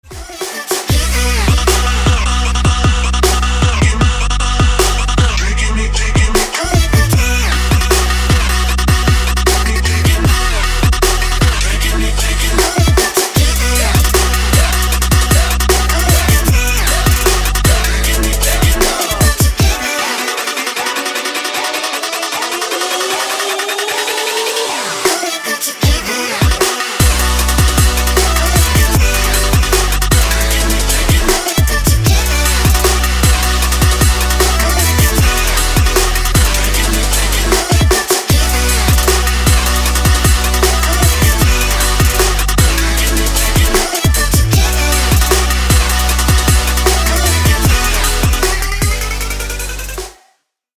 • Качество: 160, Stereo
Trap
club
future bass